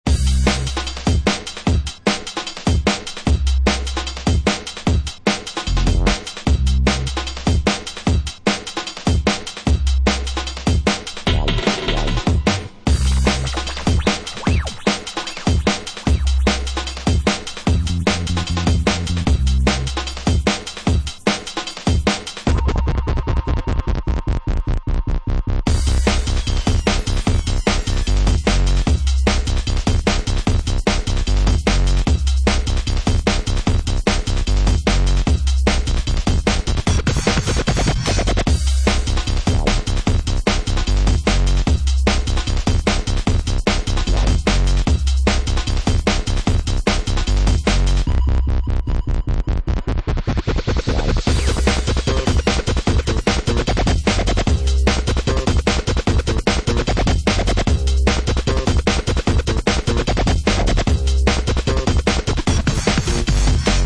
UK Garage / Breaks, Hip Hop/Dj Tools